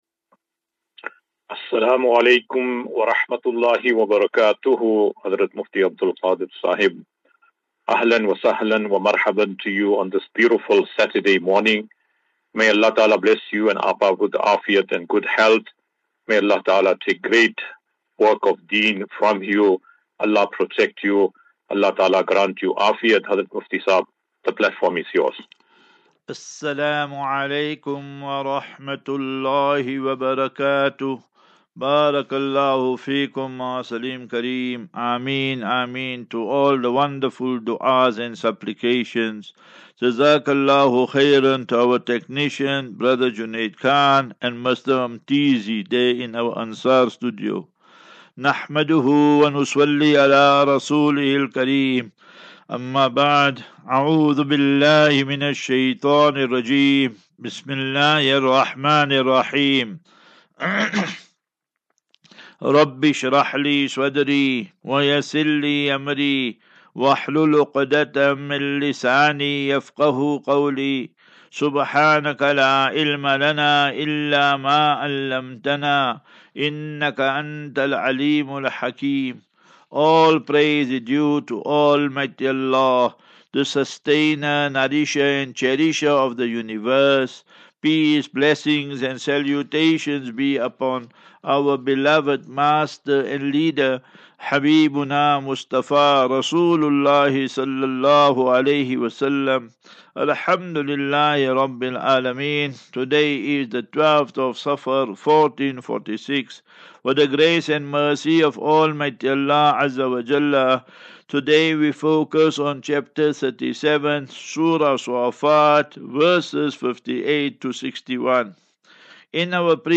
17 Aug 17 August 2024. Assafinatu - Illal - Jannah. QnA